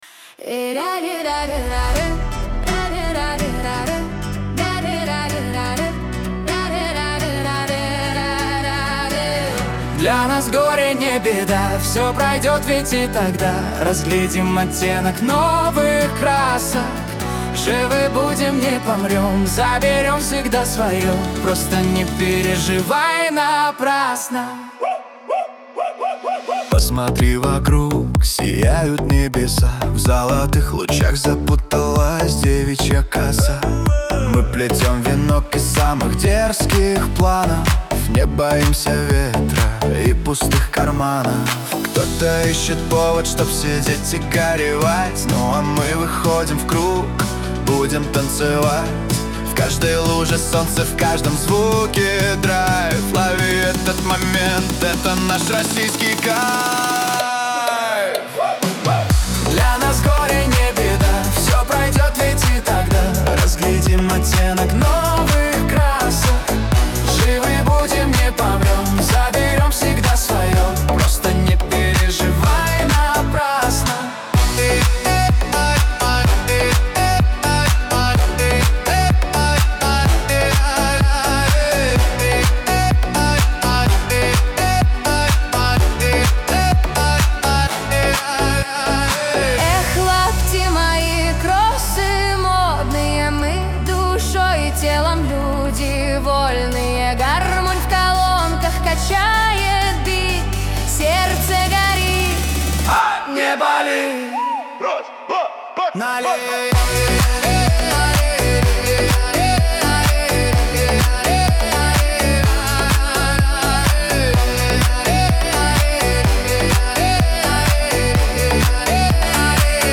ХАУС-РЭП
дуэт